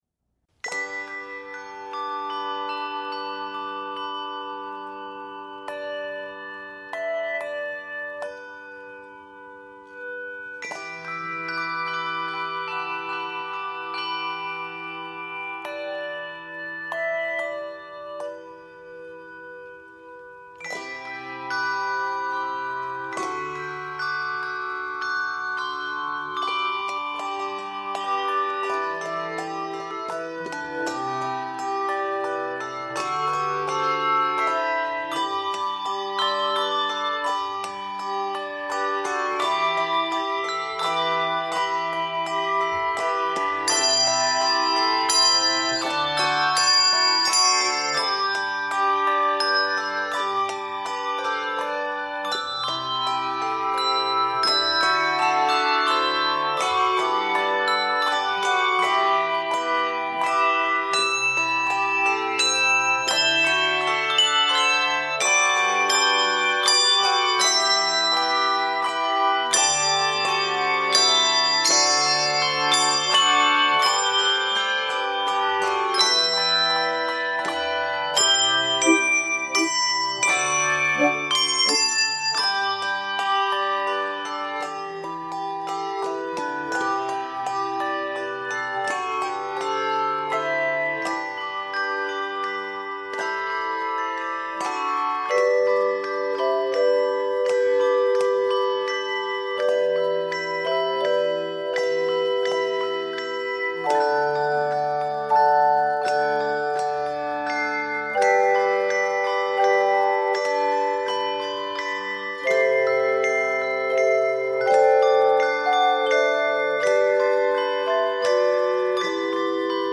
Key of G Major.